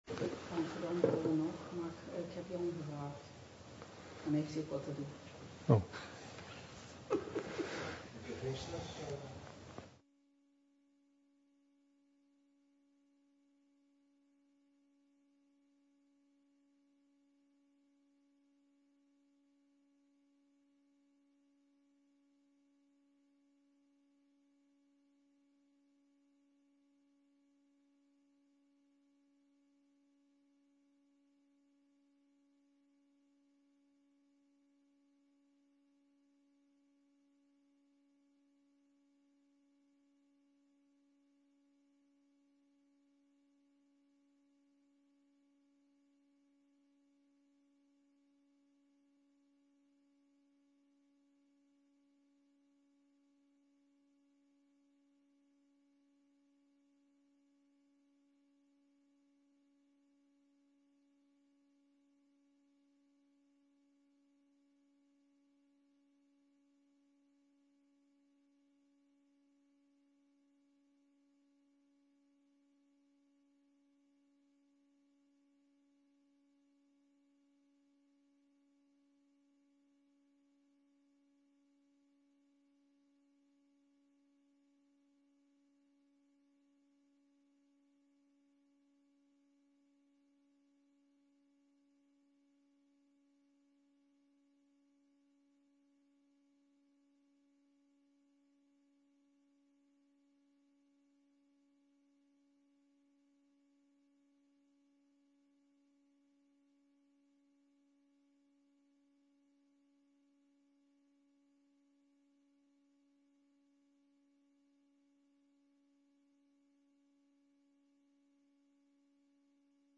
Locatie Digitale vergadering Voorzitter Henk Jan Schat